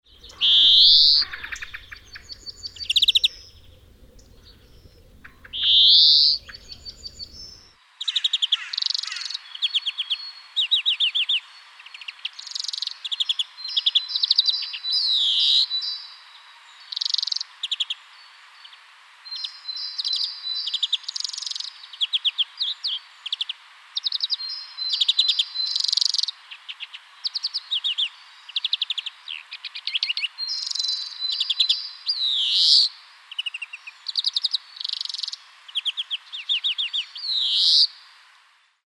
Обыкновенная зеленушка